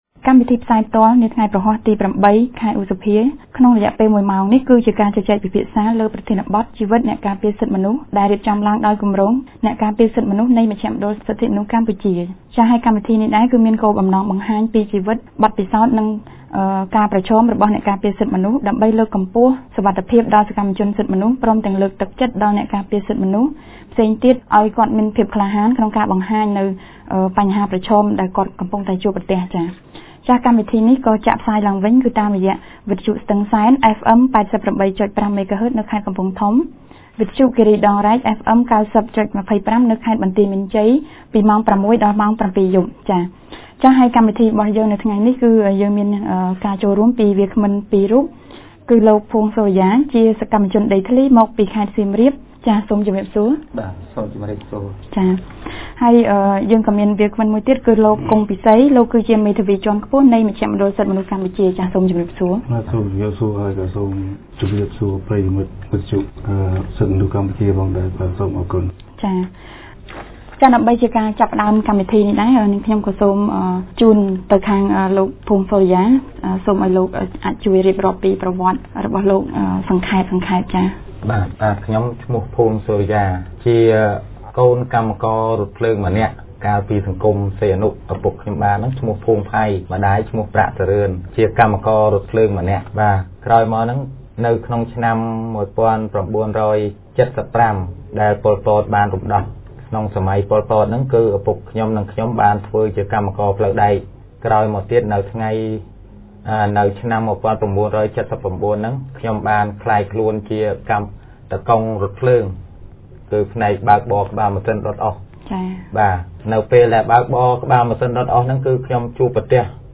នៅថ្ងៃទី០៨ ខែឧសភា ឆ្នាំ២០១៤ គម្រោងអ្នកការពារសិទ្ធិមនុស្ស បានរៀបចំកម្មវិធីពិភាក្សាផ្សាយផ្ទាល់លើប្រធានបទស្តីពី "អ្នកការពារសិទ្ធិមនុស្ស"។